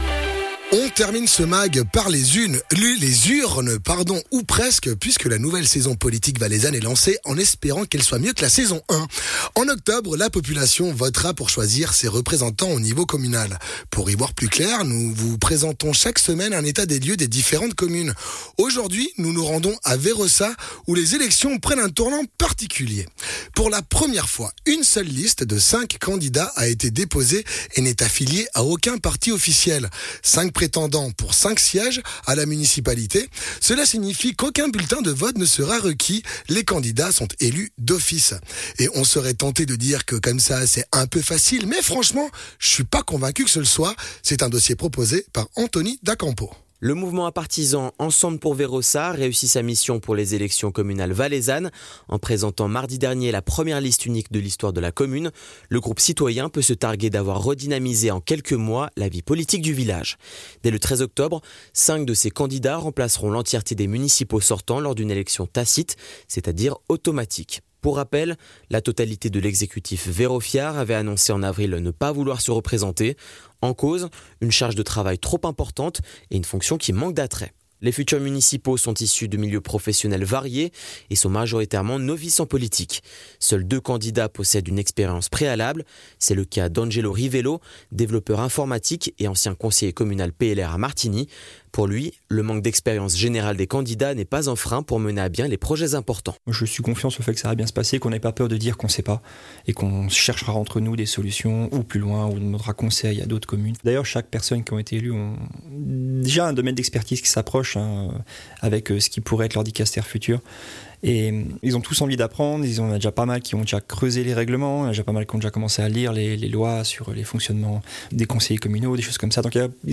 Elections communales Valais : entretien avec les candidats de Vérossaz